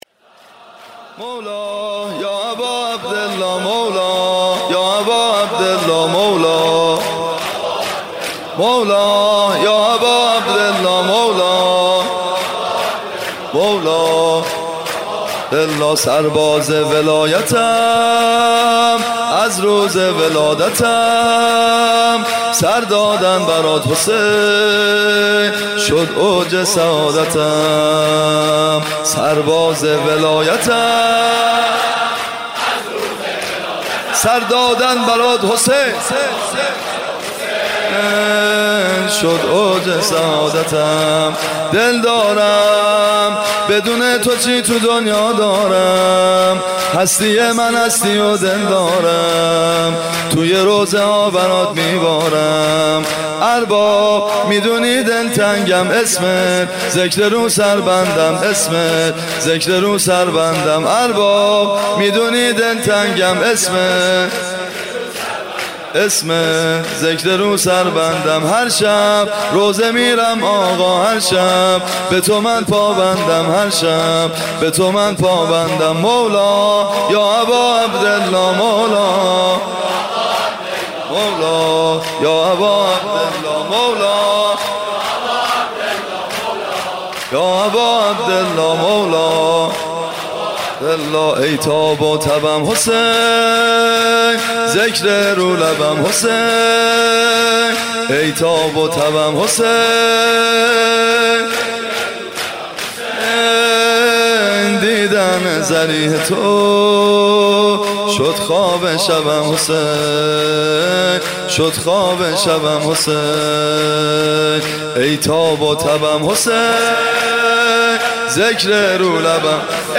شب ششم محرم95/ هیئت ابن الرضا(ع)
زمینه شب ششم محرم